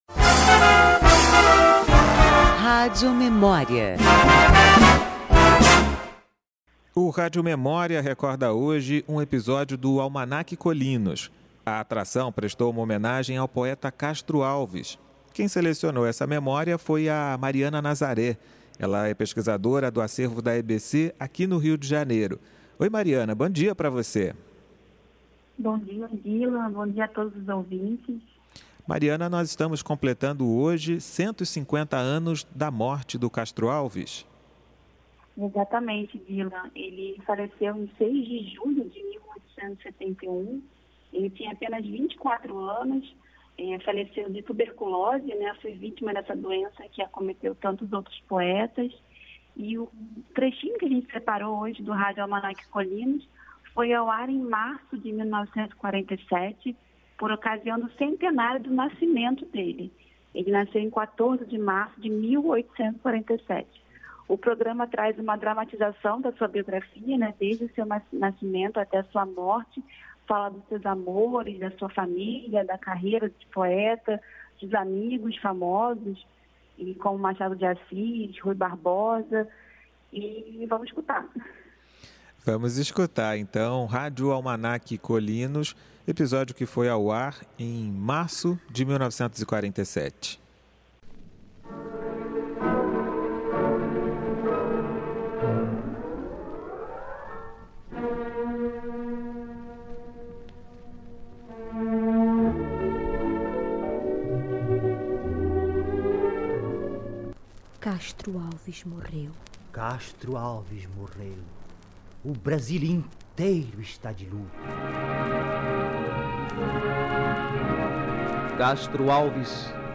E o podcast Rádio Memória, produção do Rádio Sociedade, da Rádio MEC, recuperou um trecho do Almanaque Kolynos, que, em março de 1947, homenageou o poeta pela ocasião do centenário de seu nascimento. Acompanhe uma dramatização da biografia de Castro Alves, do seu nascimento à sua morte, e fala da família, amores, de sua carreira como poeta, de sua amizade com Machado de Assis e Rui Barbosa: